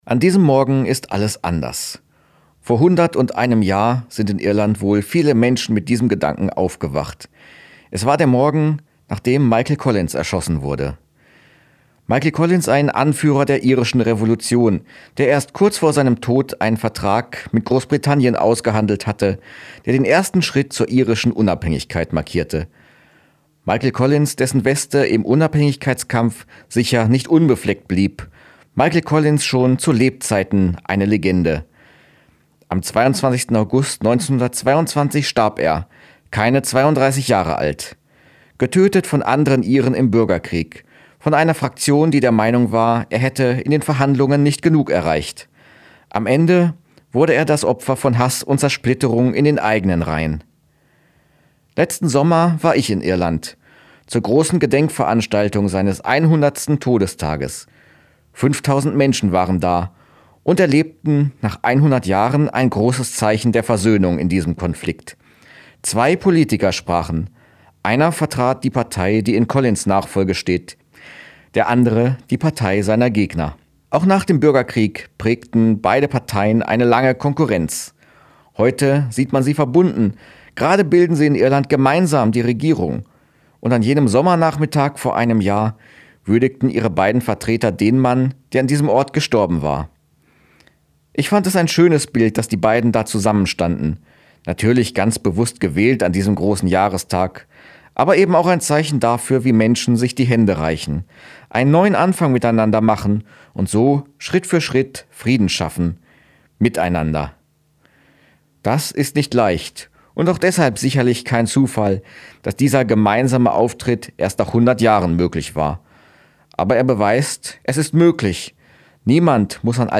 Radioandacht vom 23. August